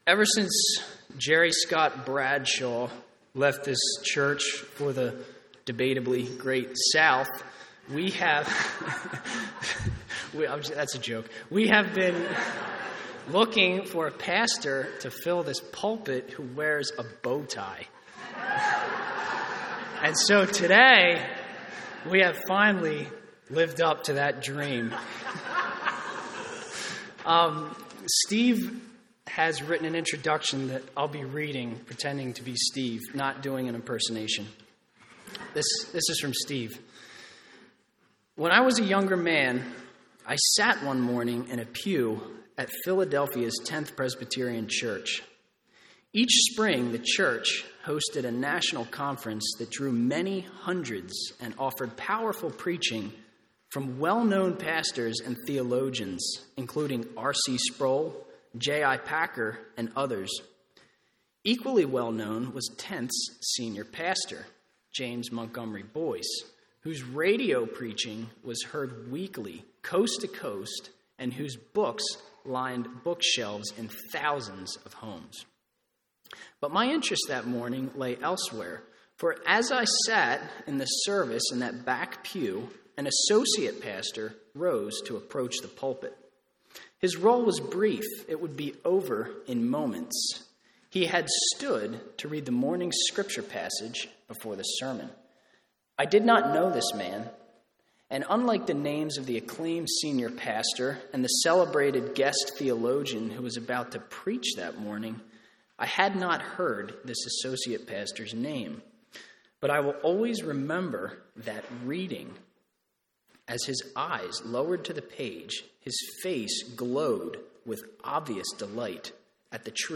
Selah” Sermon Outline 1.